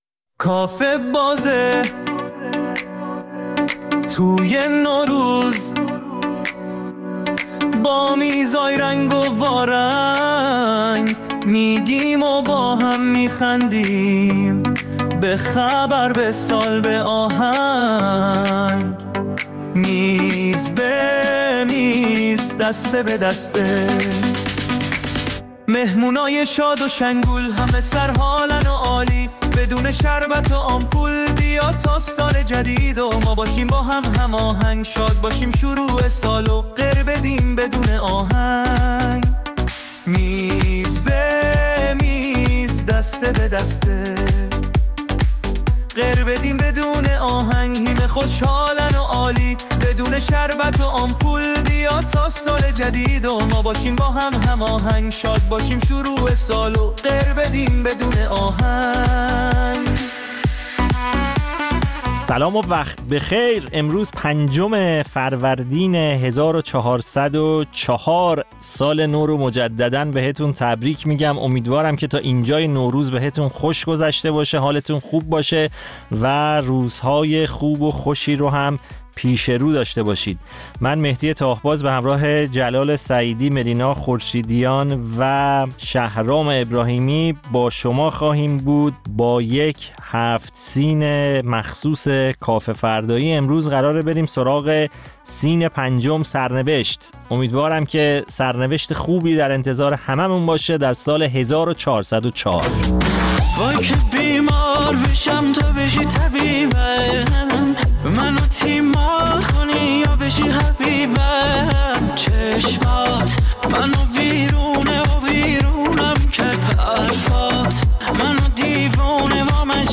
در کافه فردای نوروز با سفره هفت‌سین خاص کافه‌فردایی‌ها شوخی می‌کنیم و در میز‌های مختلف و بخش‌های متفاوت نمایشی وجوه متفاوت نوروز و تعطیلات نوروزی را در کنار هم به مرور می‌نشینیم.